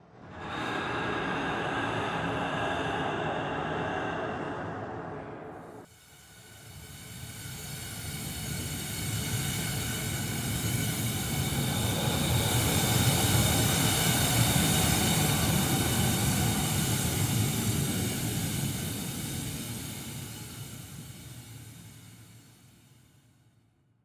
Long story short, there’s a sound effect (air being blown through a straw) that I want to tune to a certain pitch (E).
Straw effect attached.